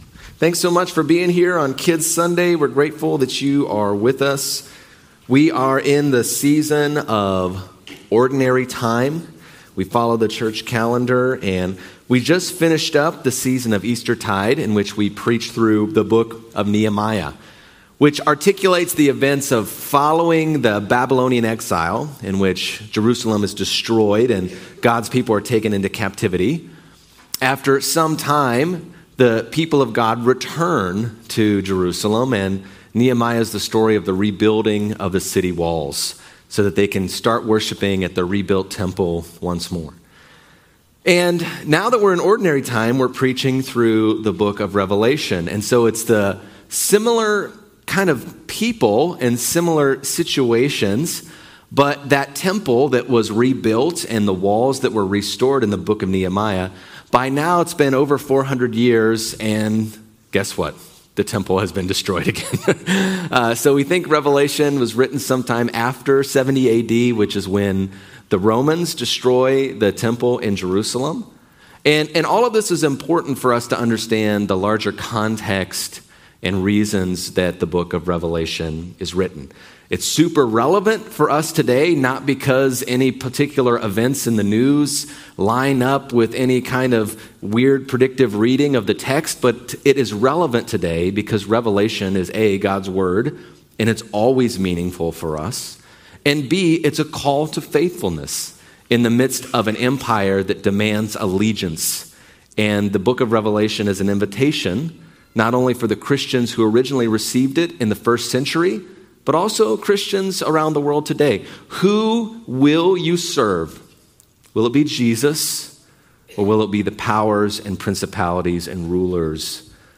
Sermons - Park Street Brethren Church